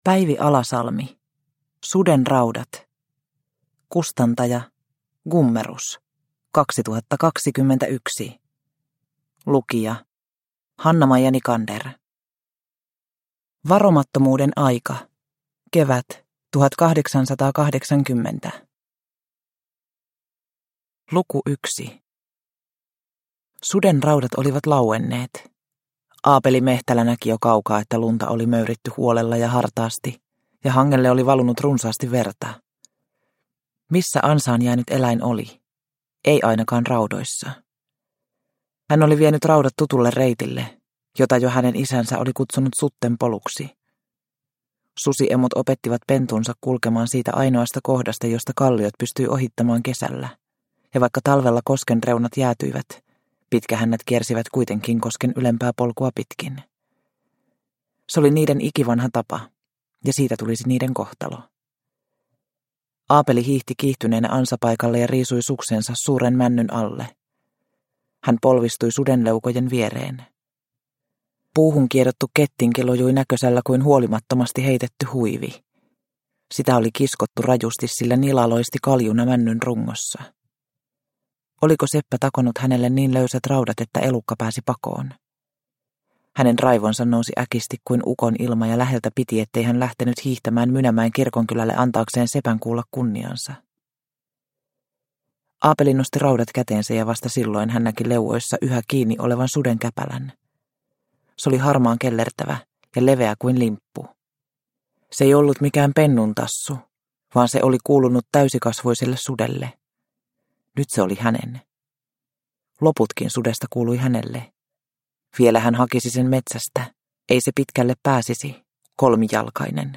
Sudenraudat – Ljudbok – Laddas ner